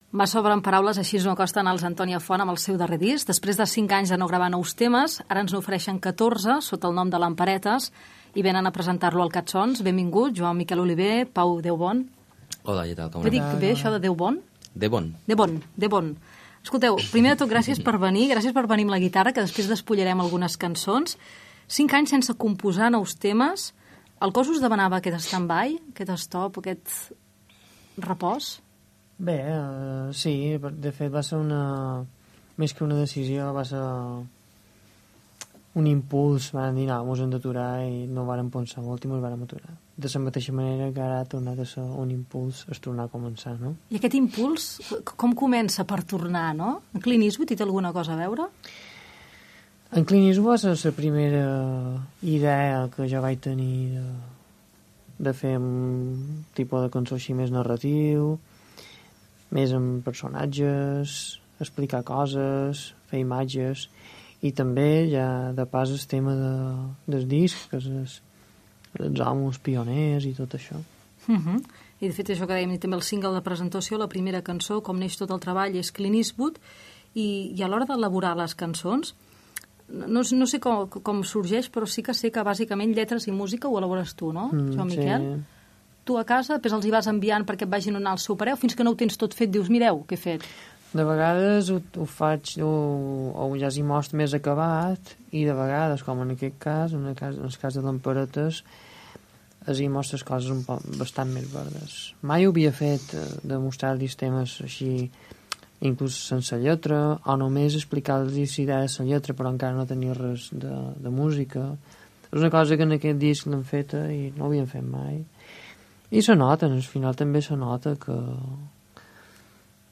Entrevista a Joan Miquel Oliver i Pau Debón, del grup Antònia Font